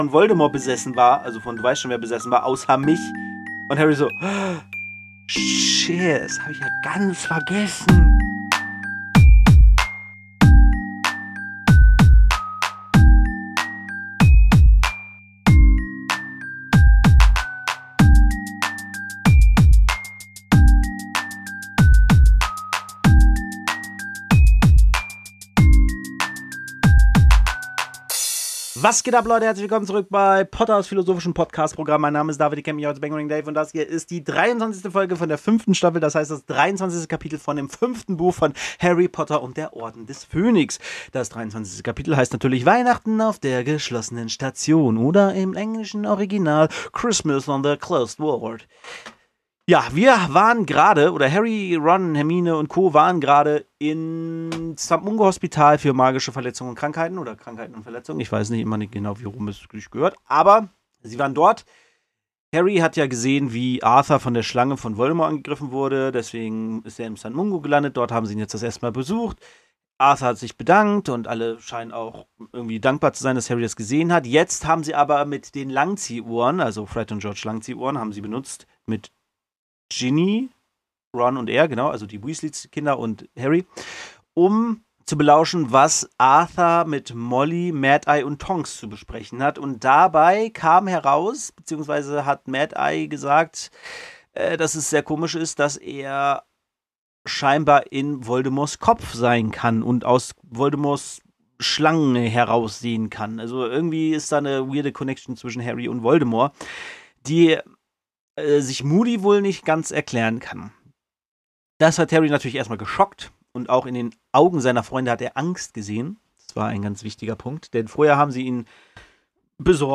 Sorry für den Sound, mein Micro war falsch hingestellt, was ich nicht bemerkt habe.